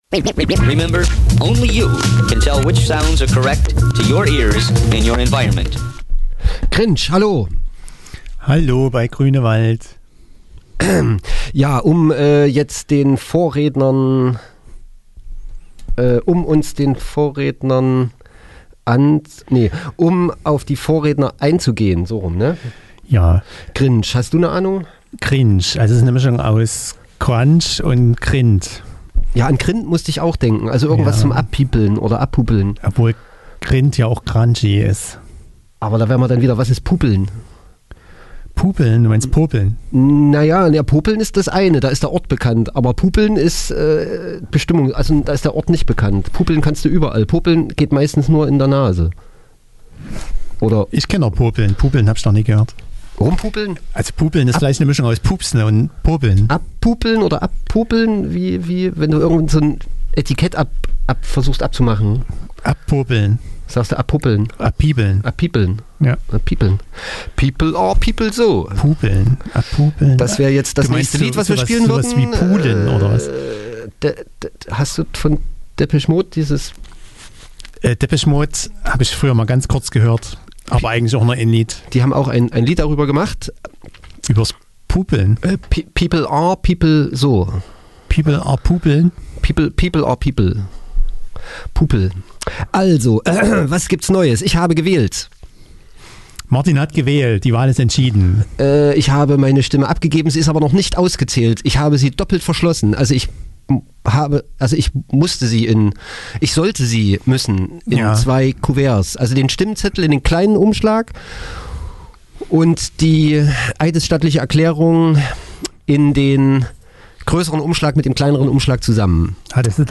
Jetzt auch im Podcast - ganz ohne nervige Musik.